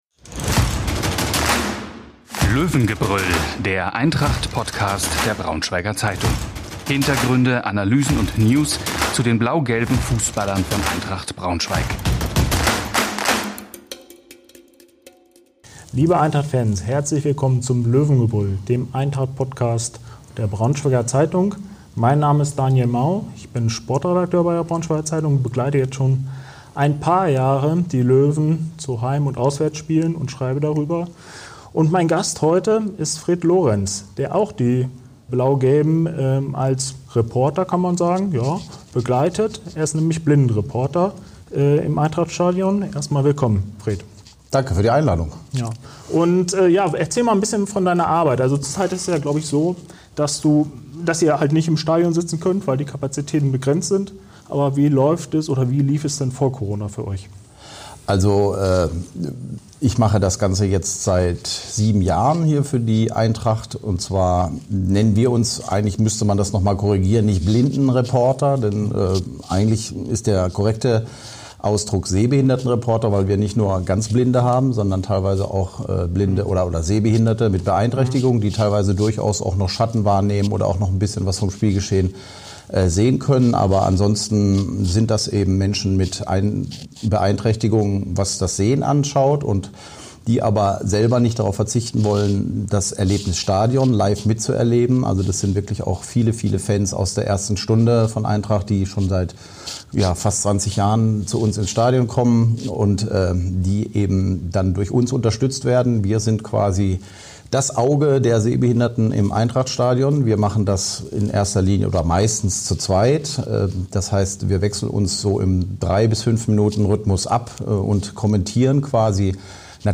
Unsere Sportredakteure diskutieren das Geschehen rund um das Stadion an der Hamburger Straße.